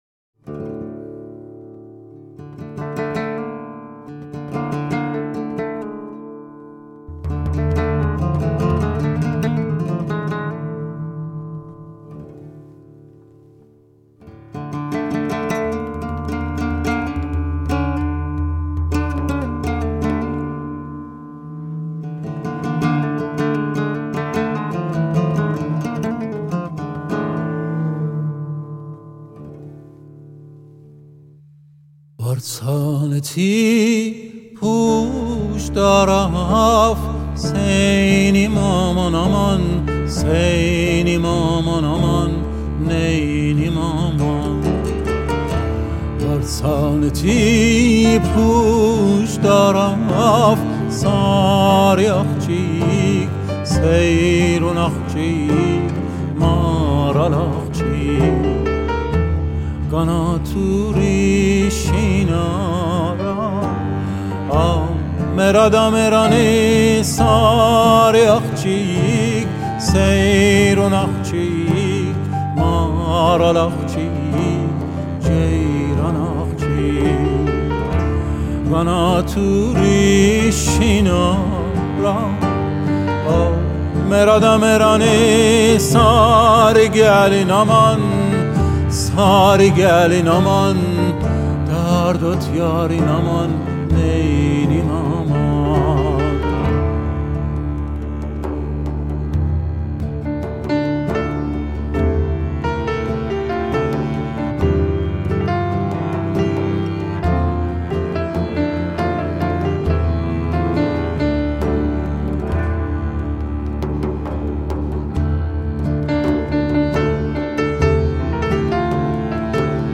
موسیقی فولکلور ایرانی